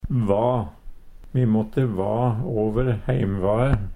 va - Numedalsmål (en-US)